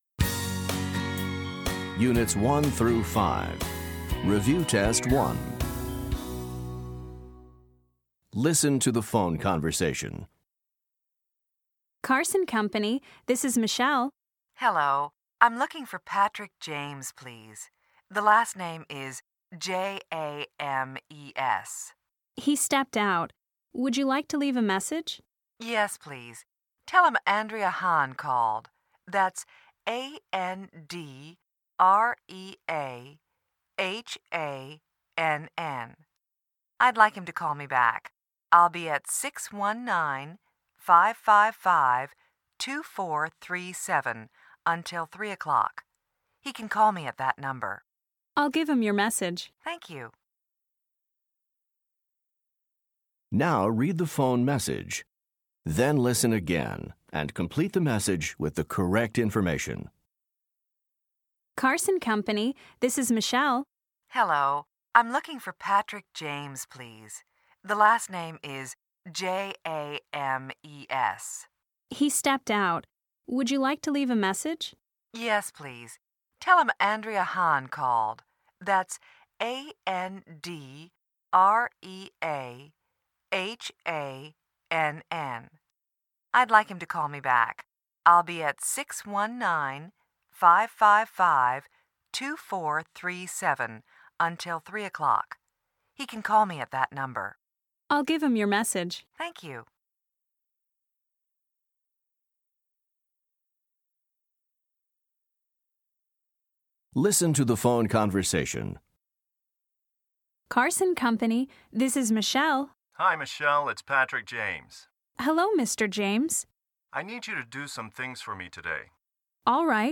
Listen to the phone conversation. Then complete the message with the correct information.